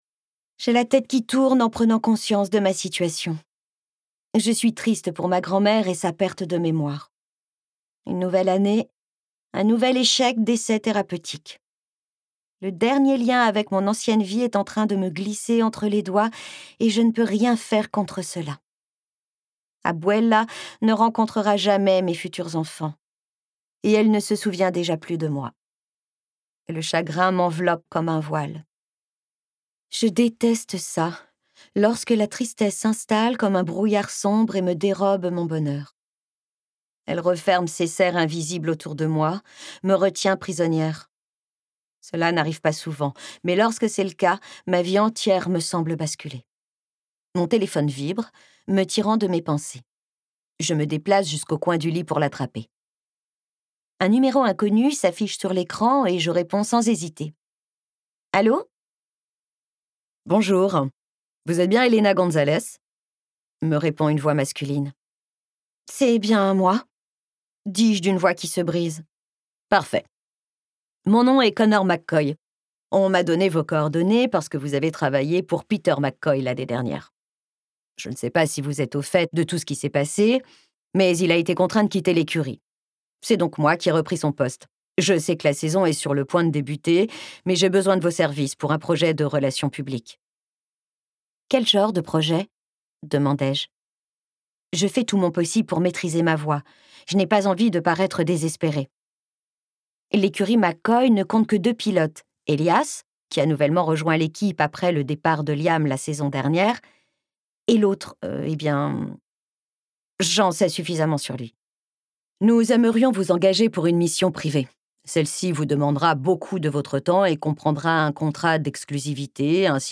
Lire un extrait Lauren Asher
Interprétation humaine Durée : 11H51 24 , 95 € précommande Ce livre est accessible aux handicaps Voir les informations d'accessibilité